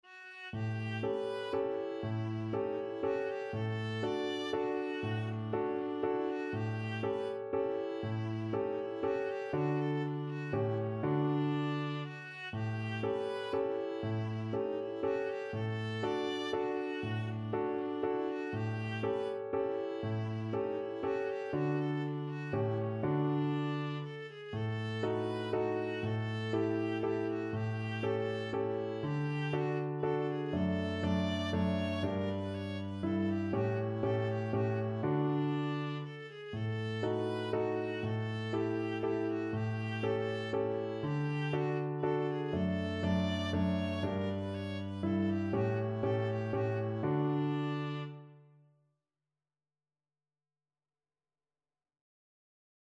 Viola
3/4 (View more 3/4 Music)
D major (Sounding Pitch) (View more D major Music for Viola )
Easy Level: Recommended for Beginners with some playing experience
Classical (View more Classical Viola Music)